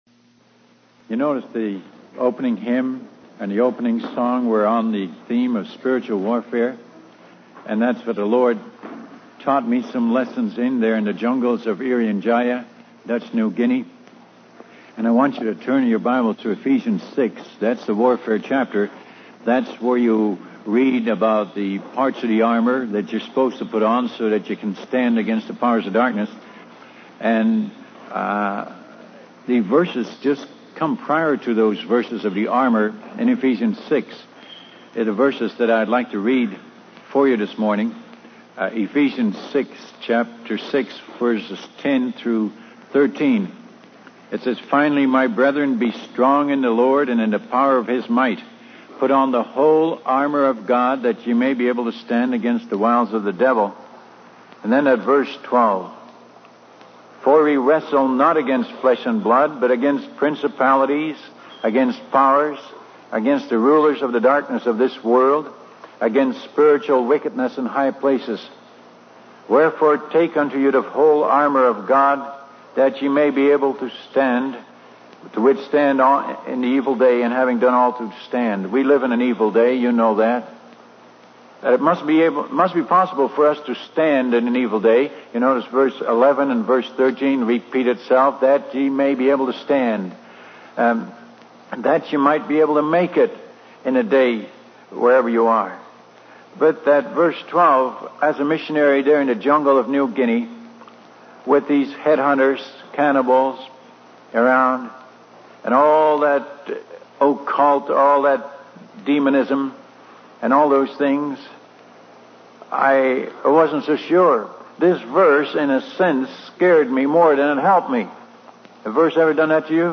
In this sermon, the speaker shares a personal story of facing trials and temptations and feeling like a failure. He relates to the struggles of the Israelites in the wilderness and the Apostle Paul's struggle with sin in Romans 7.